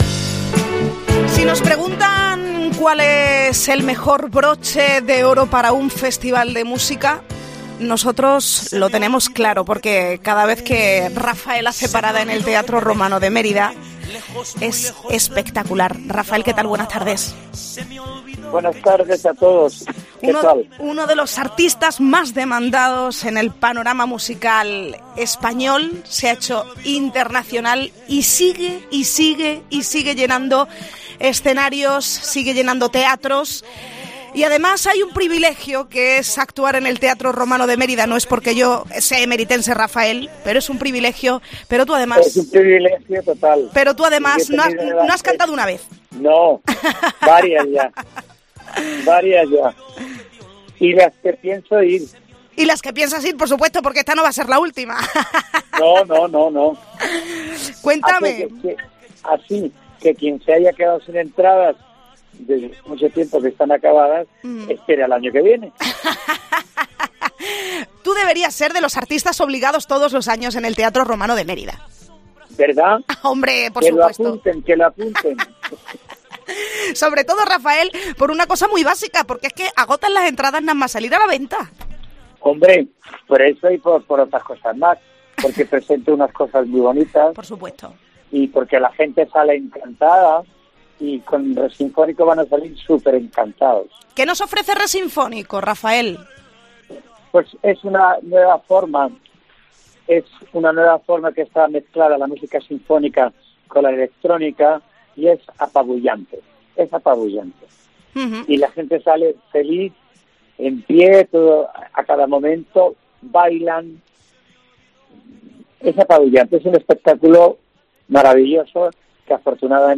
Entrevista | Raphael: "Es un privilegio poder actuar de nuevo en el Teatro Romano de Mérida"